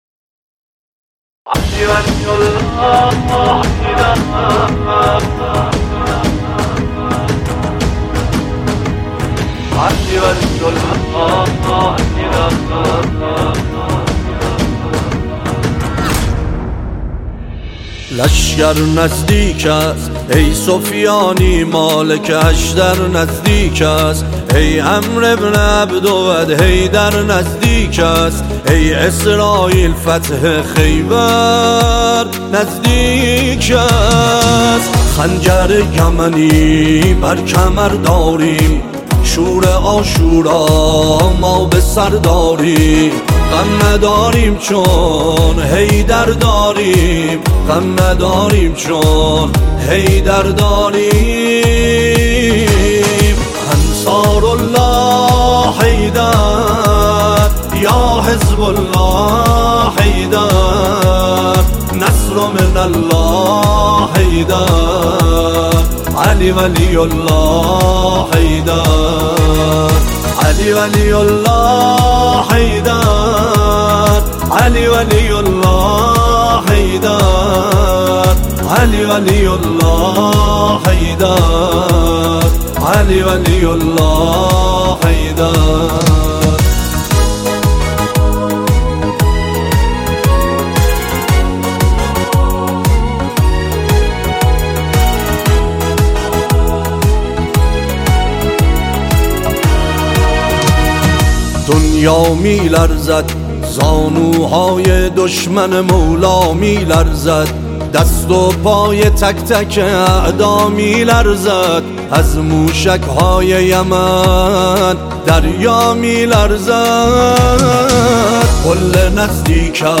نماهنگ حماسی